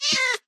Sound / Minecraft / mob / cat / hit1.ogg
hit1.ogg